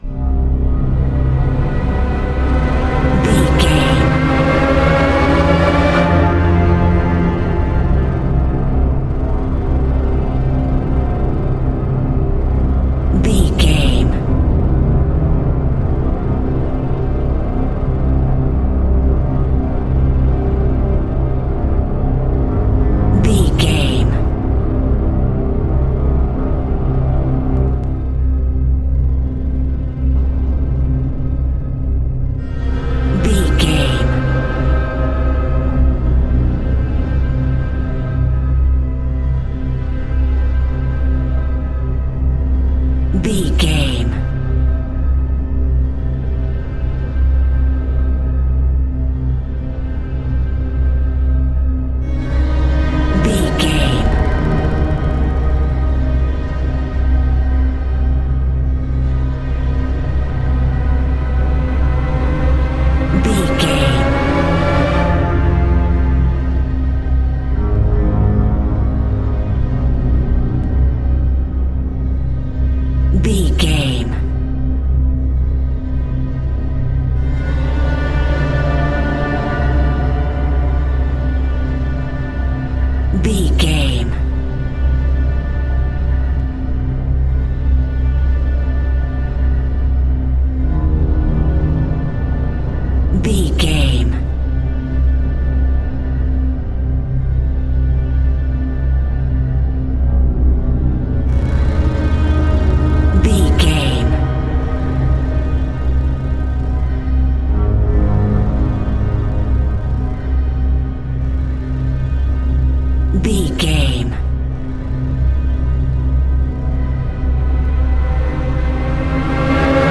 In-crescendo
Aeolian/Minor
scary
tension
ominous
dark
suspense
eerie
strings
horror
synth
pads